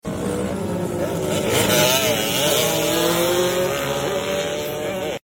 Suzuki ax100 vs cg itálica titan 300cc 400cc 4 stroke motorcycle & scooter